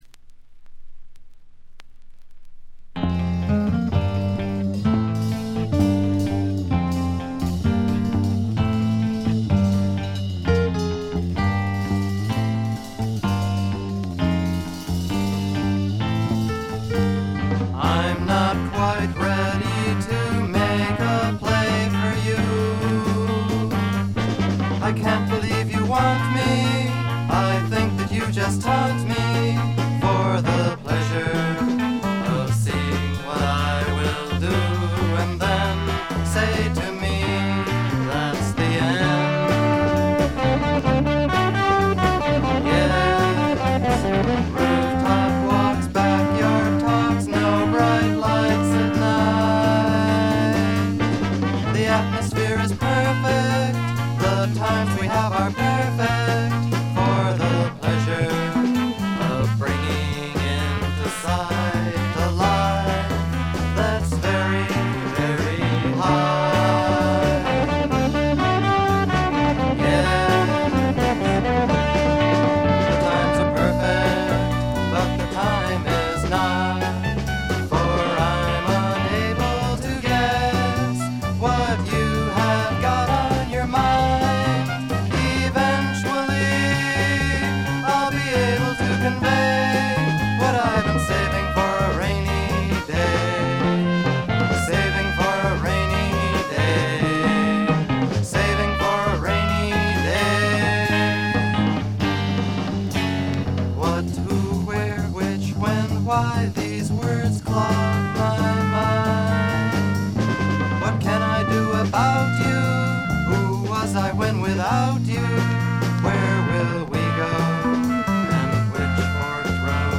B1終盤で5回ほどの周回ノイズ。
昔からアシッドフォークの定番扱いされてきた名盤です。
試聴曲は現品からの取り込み音源です。